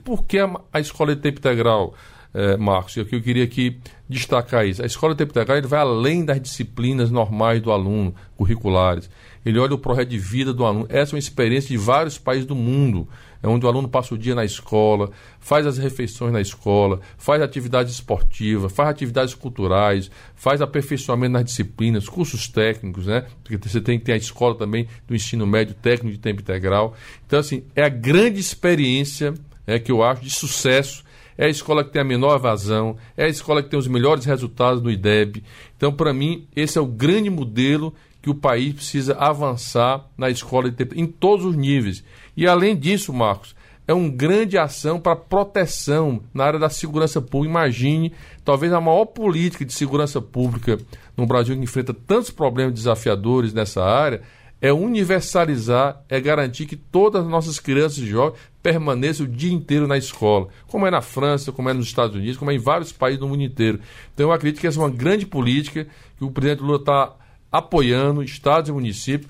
Trecho da participação do ministro da Educação, Camilo Santana, no programa "Bom Dia, Ministro" desta quarta-feira (15), nos estúdios da EBC, em Brasília.